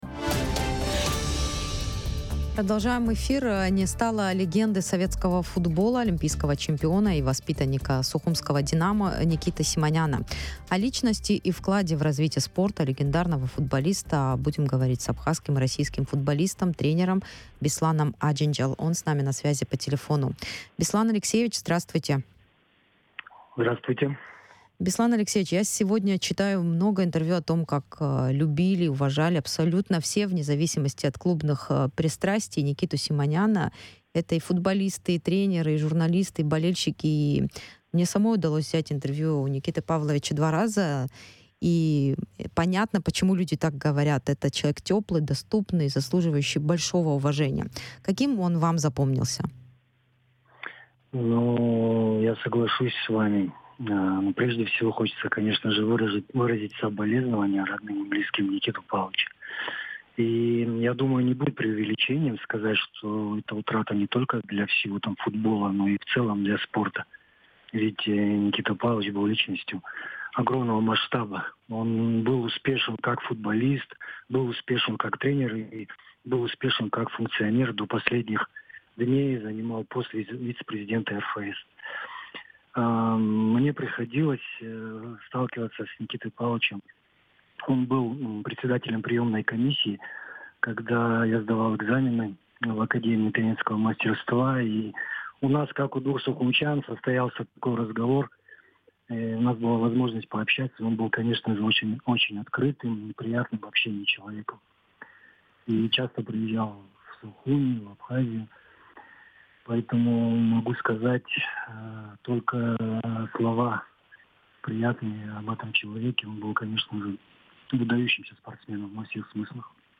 Беслан Аджинджал, абхазский и российский футболист, тренер